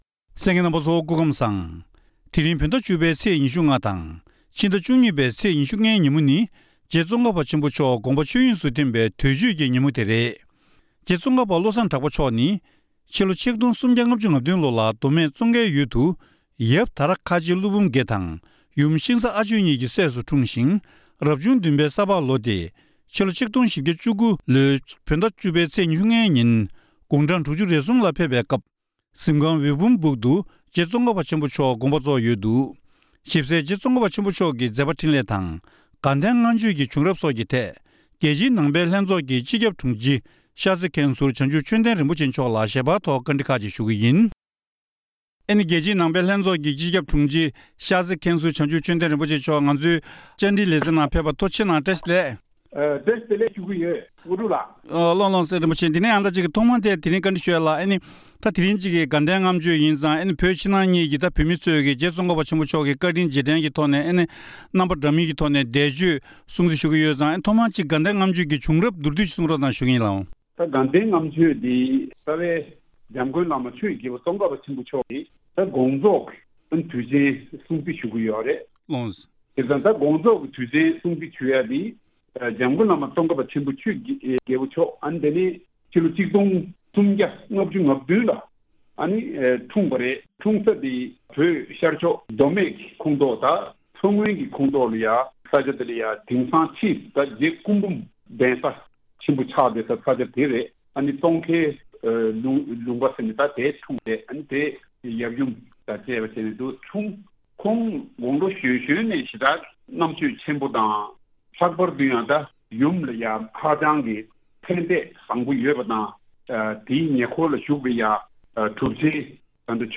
གནས་འདྲིའི་ལེ་ཚན་ནང་།